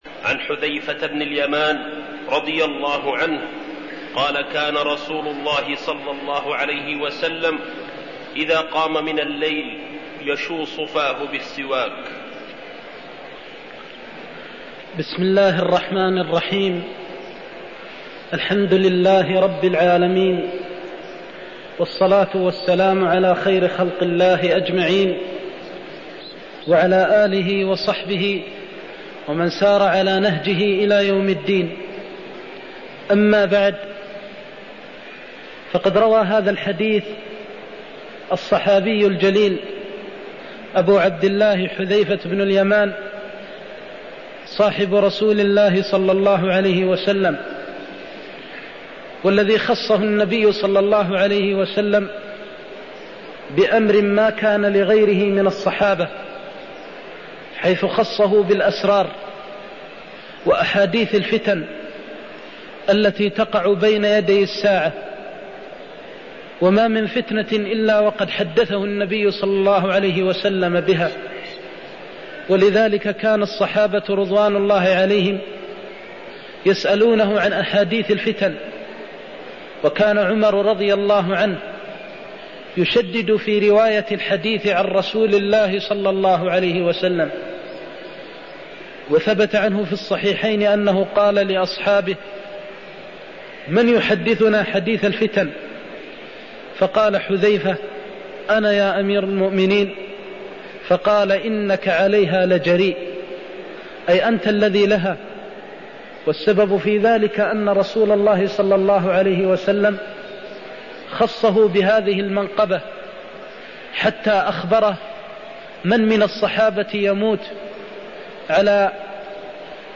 المكان: المسجد النبوي الشيخ: فضيلة الشيخ د. محمد بن محمد المختار فضيلة الشيخ د. محمد بن محمد المختار كان إذاقام من الليل يشوص فاه بالسواك (19)